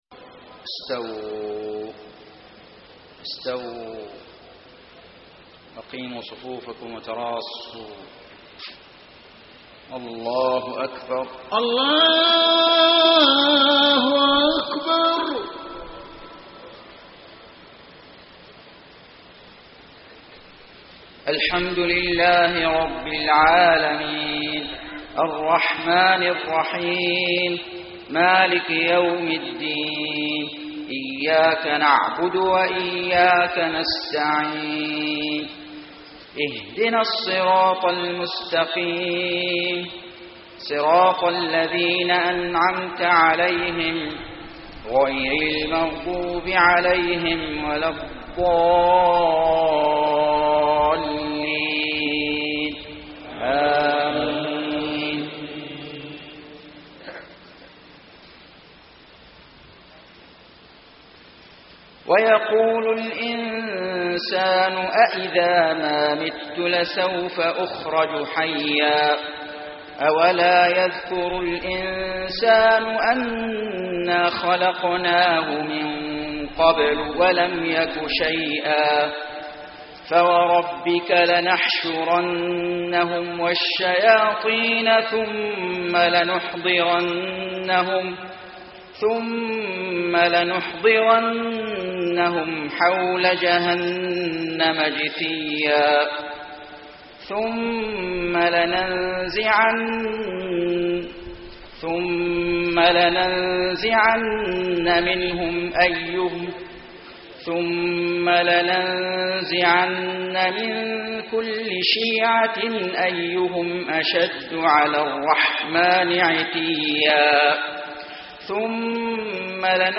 صلاة المغرب 6-6-1434 من سورة مريم > 1434 🕋 > الفروض - تلاوات الحرمين